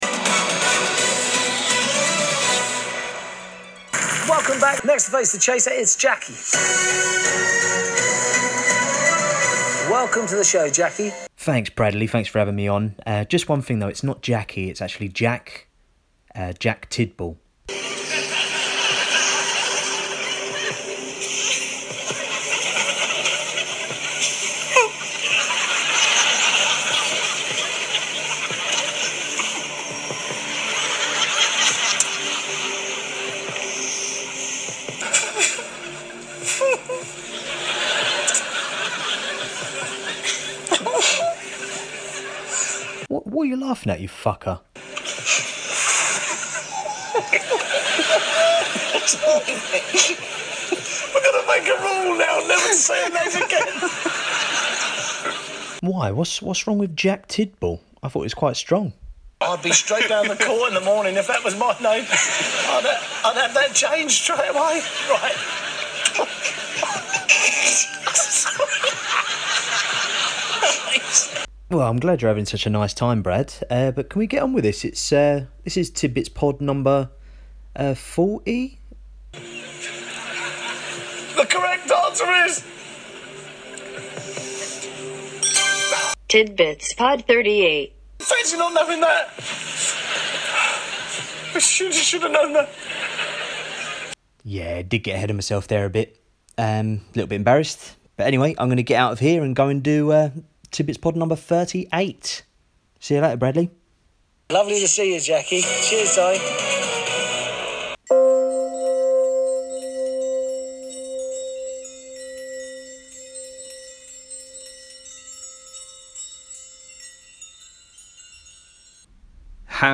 Recorded at home